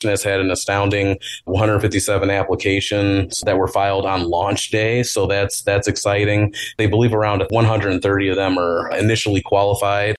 Kalamazoo County Administrator Kevin Catlin gave the county board an update Tuesday night.